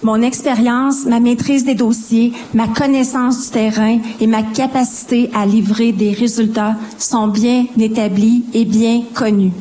Quelques flèches ont été lancées l’un envers l’autre lors du débat entre les candidats à la mairie de Bécancour qui se tenait mercredi en soirée.
À la fin du débat, les deux candidats se sont adressés aux életeurs.